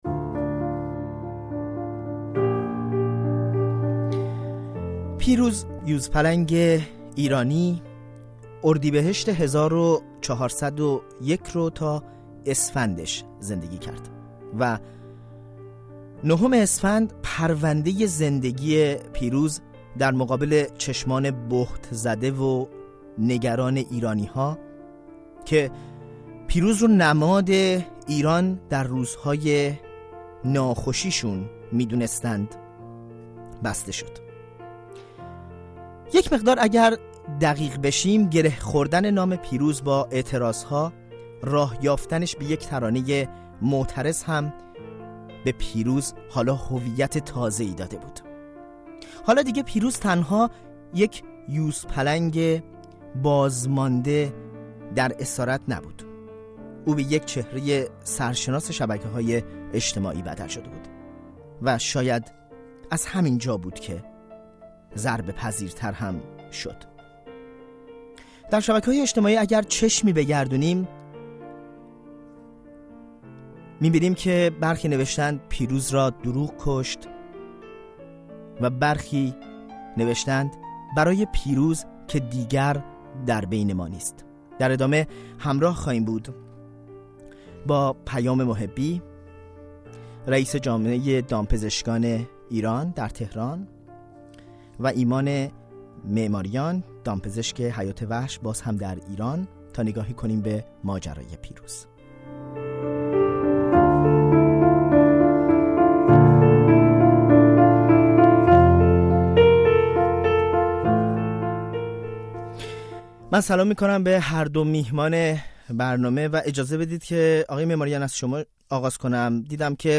میزگرد رادیویی؛ زوایای ناگفته و تاریک مرگ «پیروز»